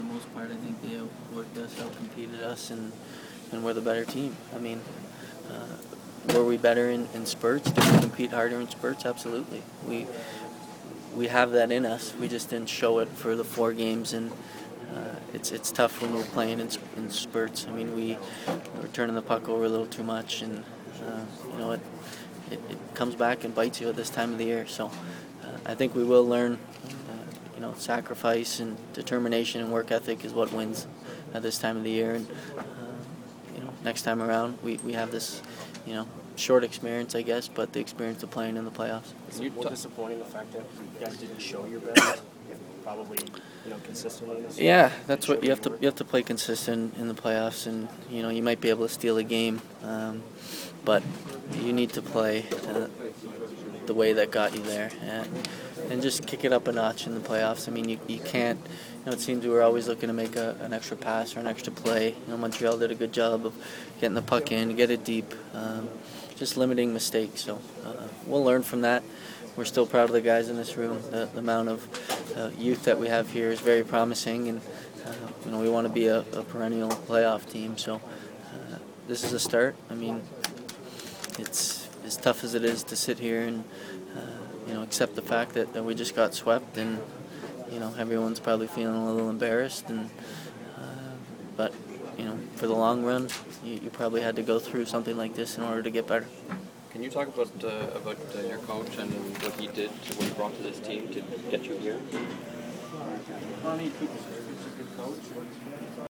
Stamkos 4/22 Post-game